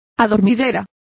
Complete with pronunciation of the translation of poppy.